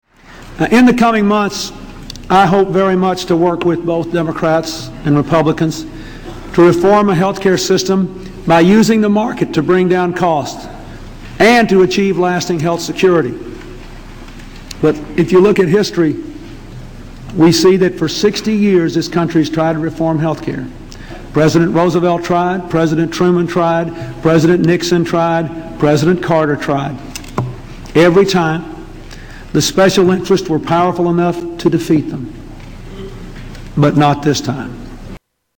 Tags: Bill Clinton State of the Union Bill Clinton State of the Union Address Bill Clinton speech President